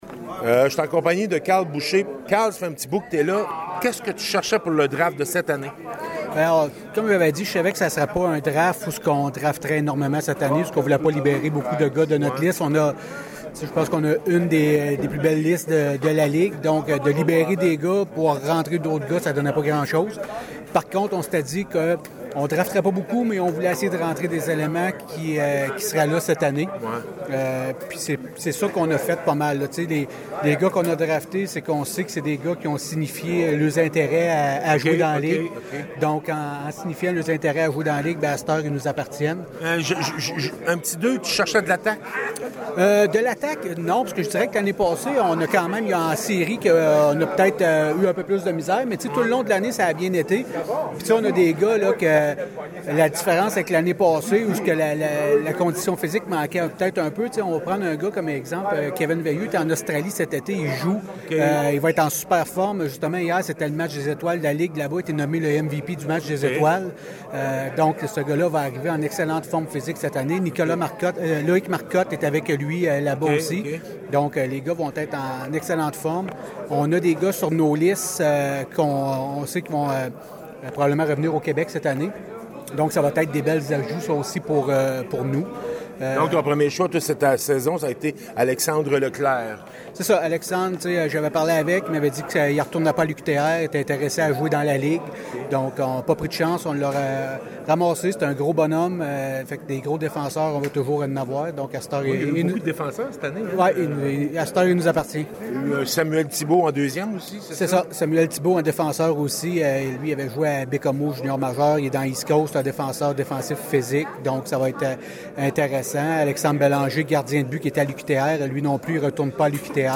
Entrevues audios (MP3) avec les directeurs généraux de la LNAH